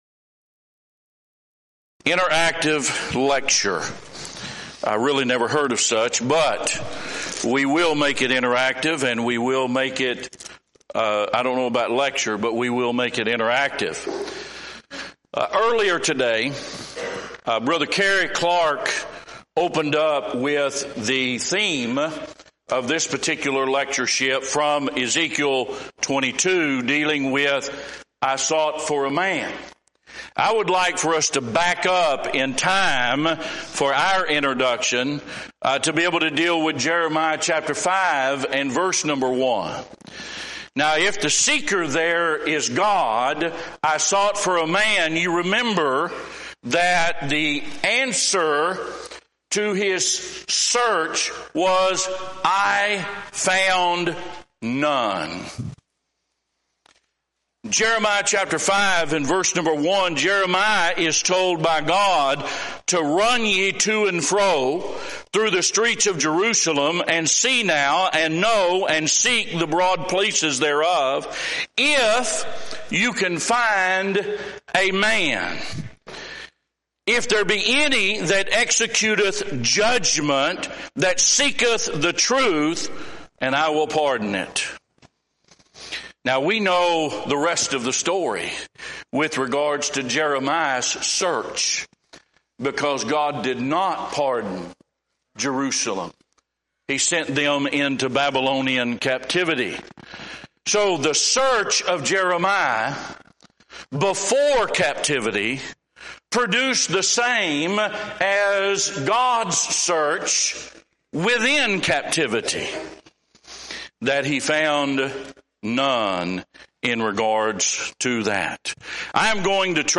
Event: 6th Annual BCS Men's Development Conference
lecture